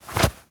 foley_cloth_light_fast_movement_11.wav